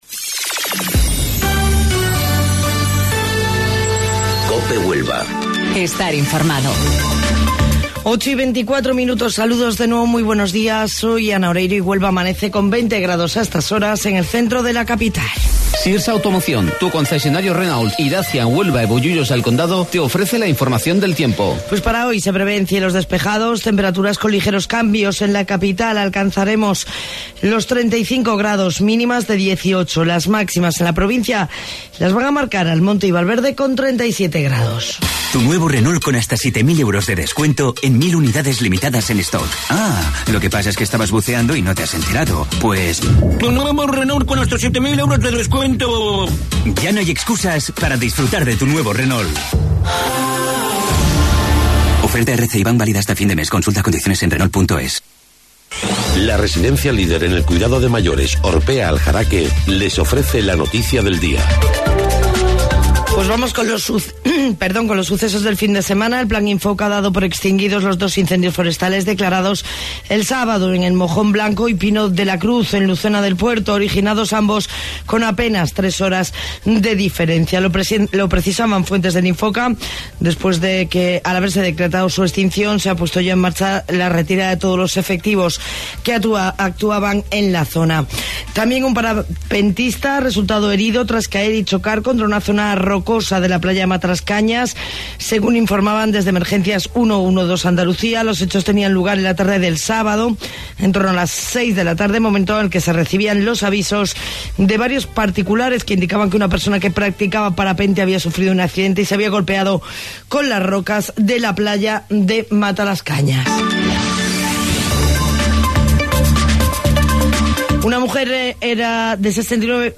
AUDIO: Informativo Local 08:25 del 5 de Agosto